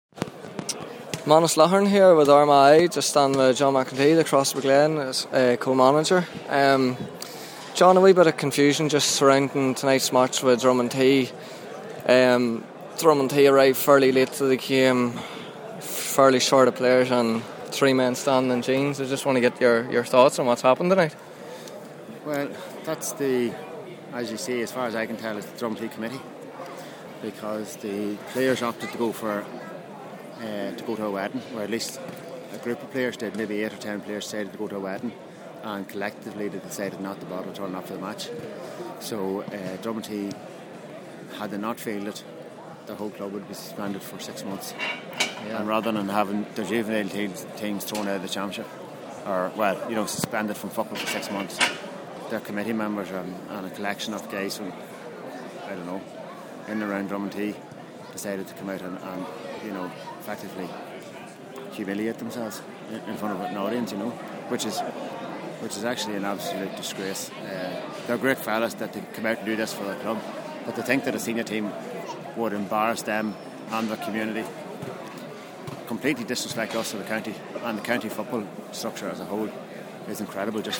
My interview